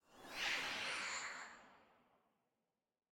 Minecraft Version Minecraft Version snapshot Latest Release | Latest Snapshot snapshot / assets / minecraft / sounds / block / dried_ghast / ambient2.ogg Compare With Compare With Latest Release | Latest Snapshot